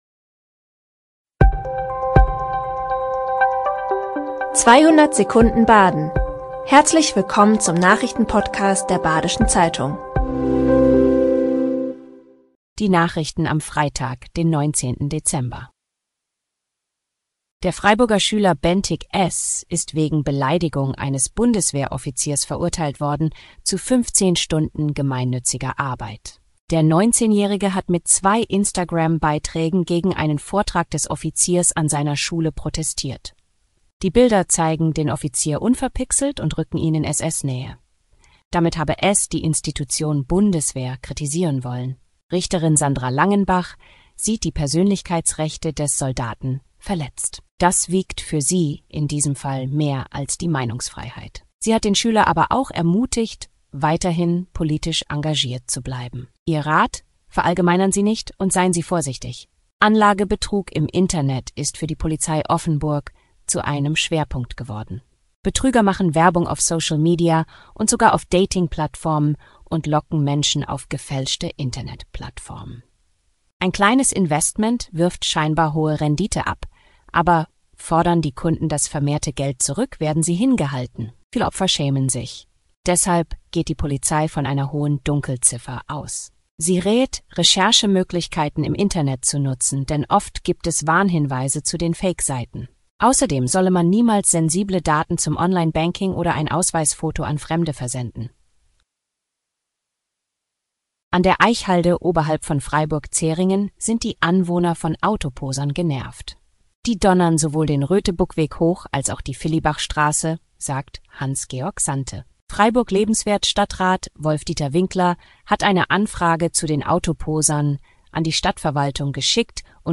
5 Nachrichten in 200 Sekunden.